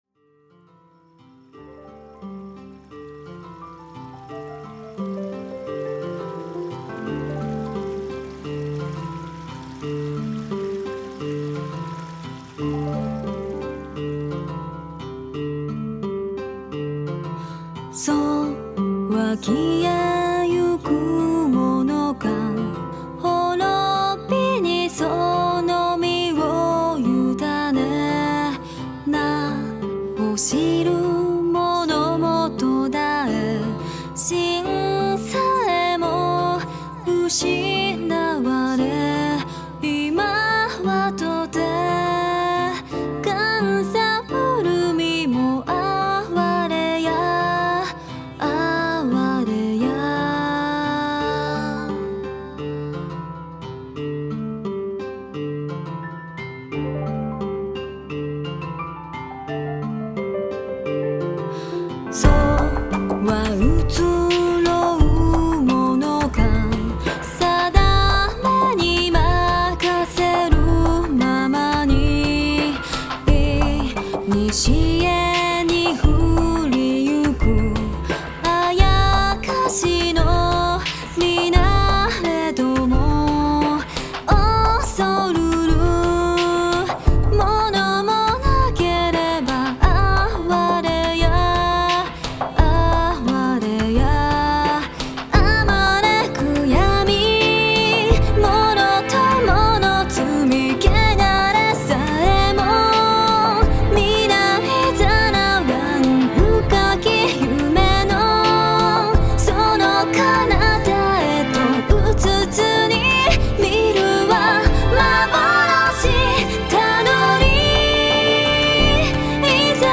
東方マルチジャンルアレンジアルバムです。
ピアノ、Strを中心にした楽曲を、やはり壮大な感じで多めに！